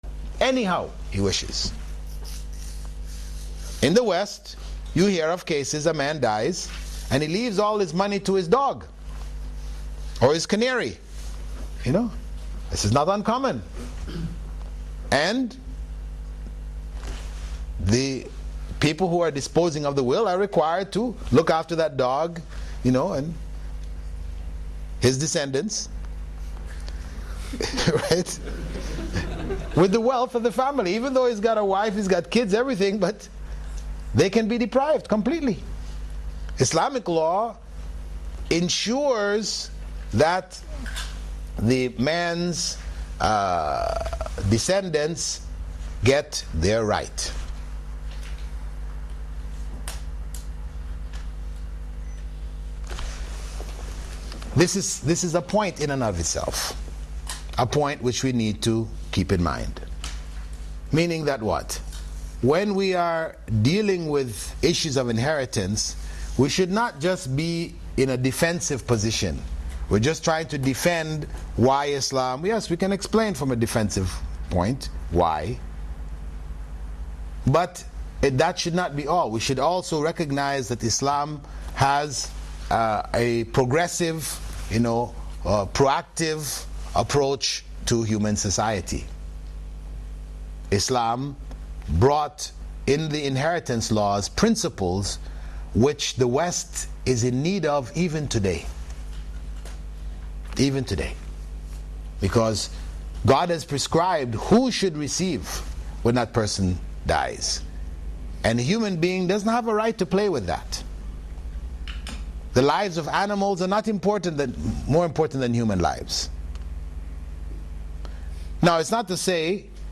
In this Video: Women's Issues by Bilal Philips (Continued) Revert Stories - Presentation 1 Misconceptions about Muslim-Behaviours by Bilal Philips Have you e...